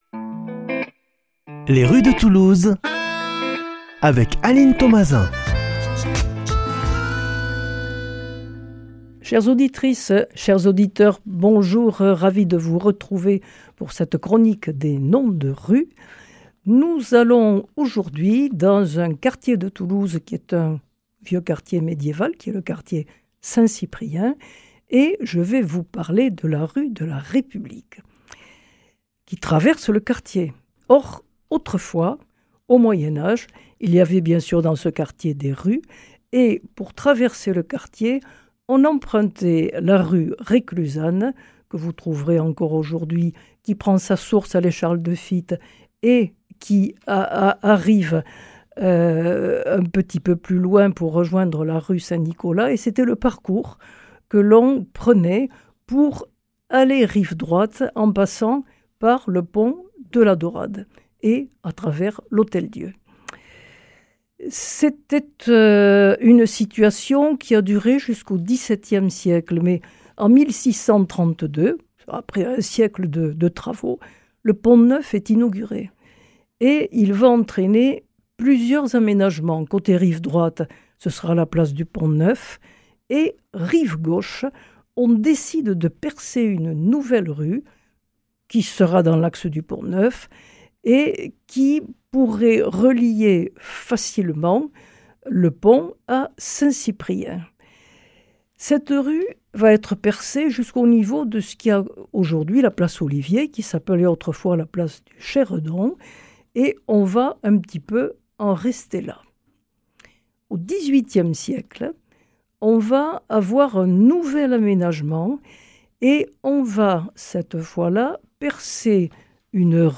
Présentatrice